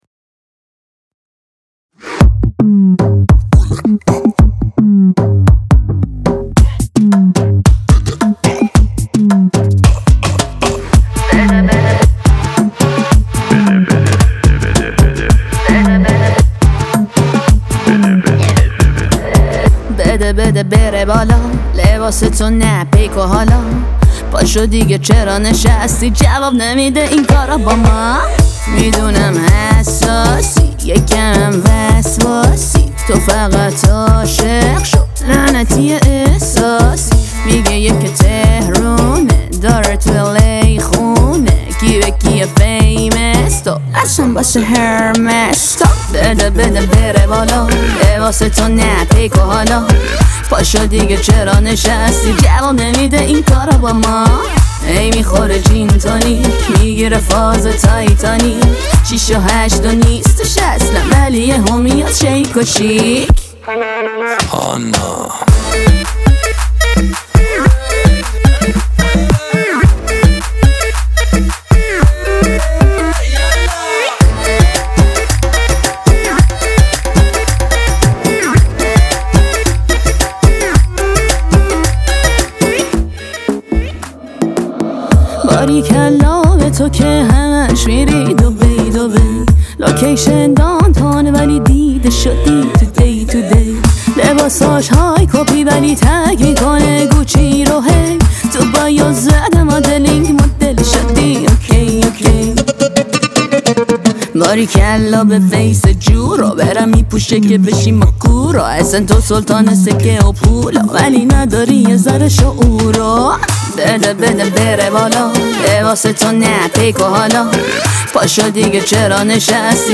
دسته : پاپ